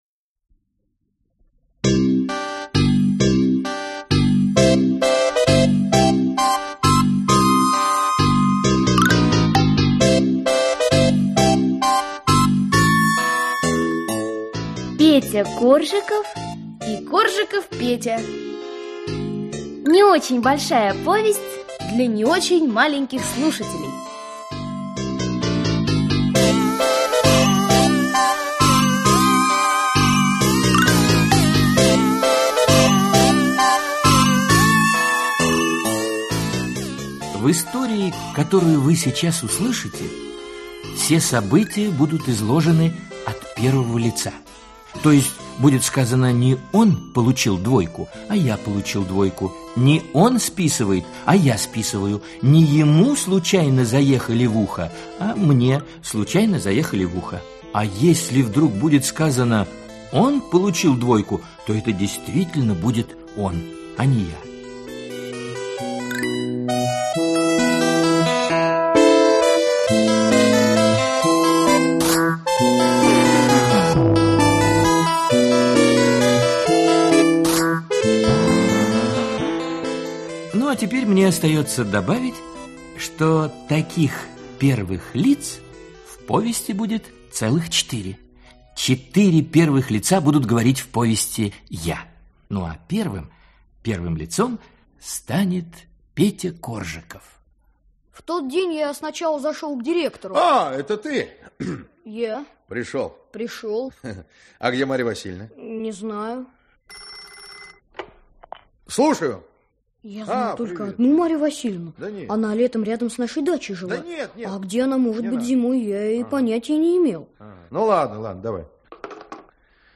Аудиокнига Петя Коржиков и Коржиков Петя | Библиотека аудиокниг
Aудиокнига Петя Коржиков и Коржиков Петя Автор Лев Давыдович Лайнер Читает аудиокнигу Актерский коллектив.